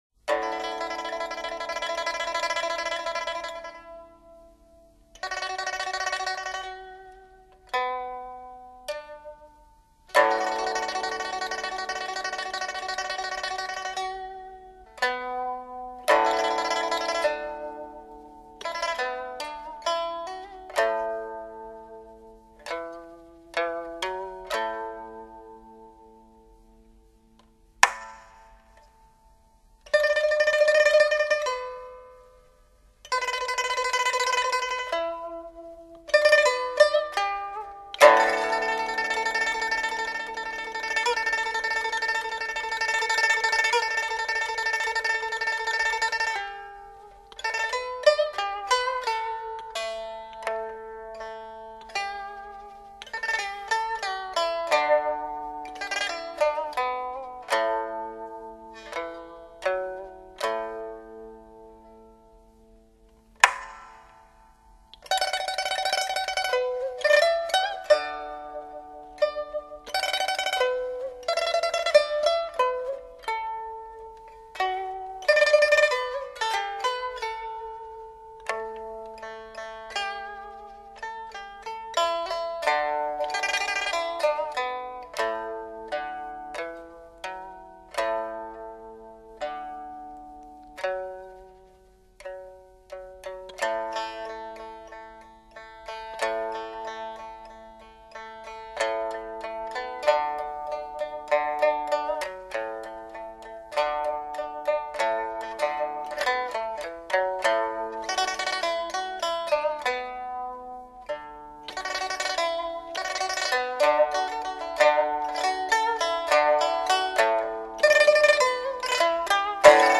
二胡
琵琶
音乐类型：民乐
乐曲开始犹如气贯山河 激昂悲愤之情尽溢 晨后又用另一种明朗而情绪论分明的韵律意境抒发